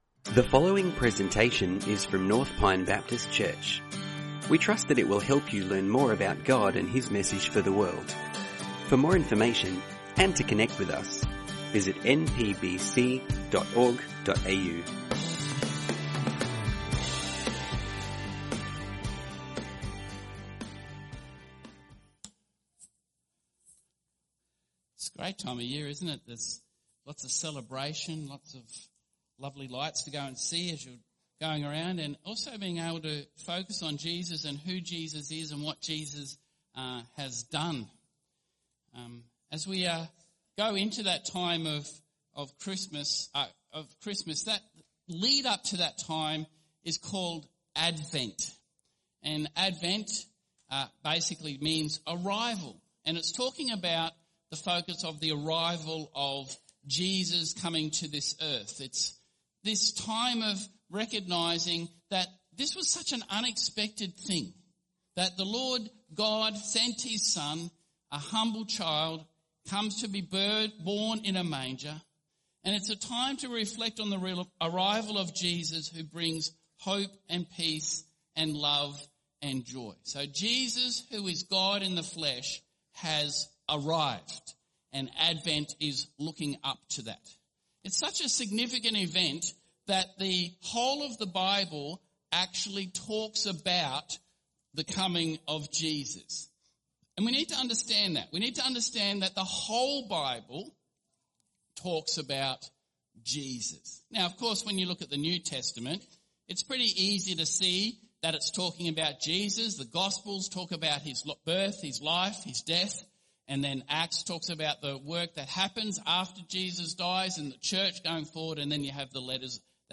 Sermons | North Pine Baptist Church